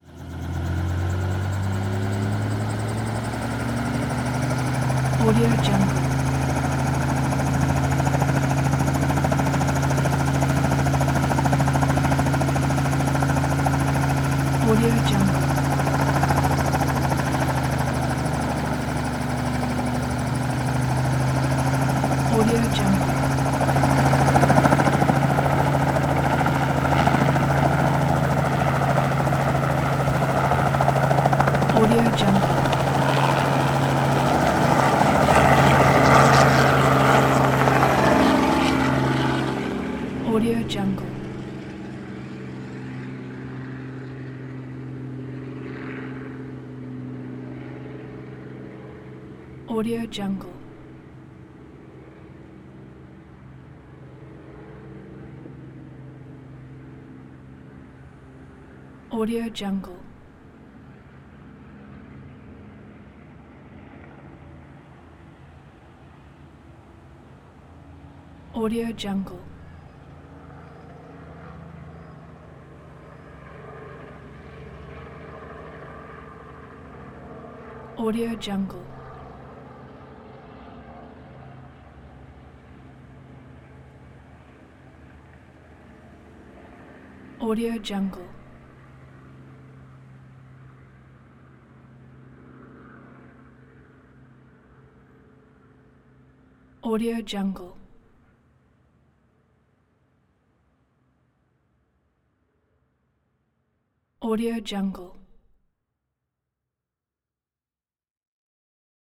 دانلود افکت صدای بلند شدن هلیکوپتر
افکت صدای بلند شدن هلیکوپتر یک گزینه عالی برای هر پروژه ای است که به صداهای بازی و جنبه های دیگر مانند صدای هلیکوپتر و نبرد نیاز دارد.
Sample rate 16-Bit Stereo, 44.1 kHz
Looped No